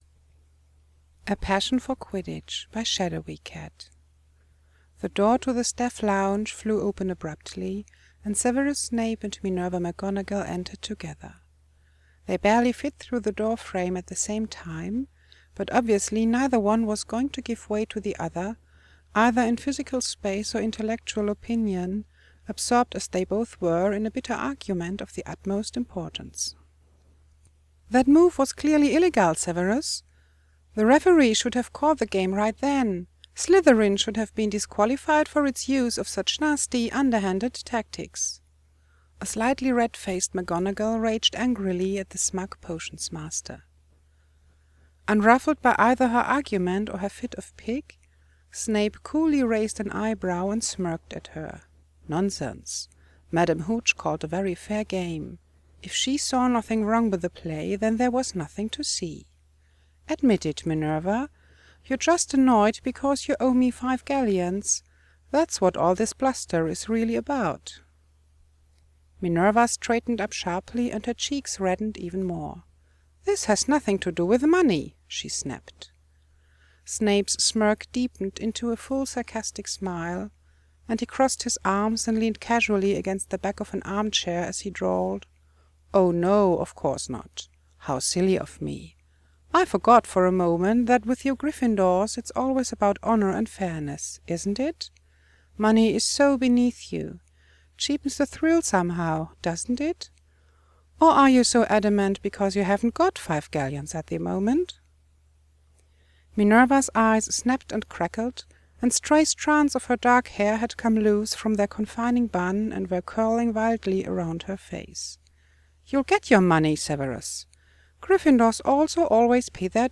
Podfic (MP3)